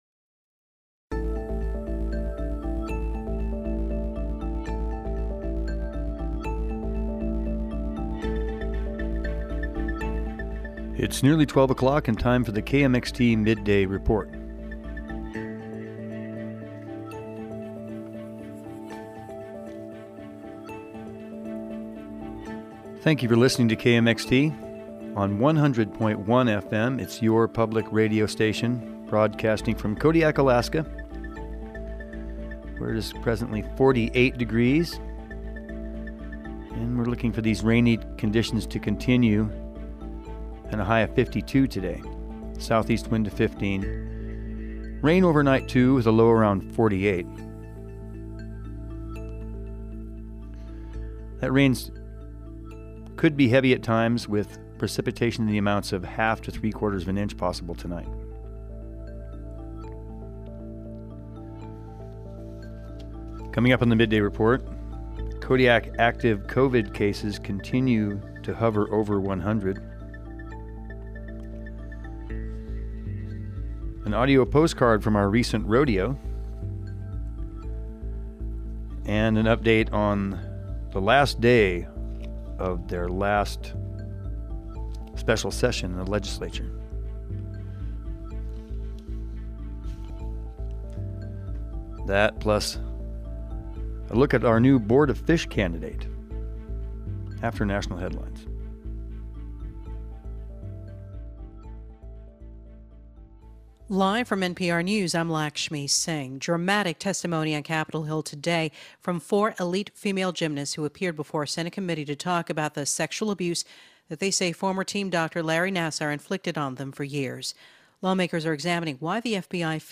KMXT Staff September 15, 2021 News, Newscasts, Newsflash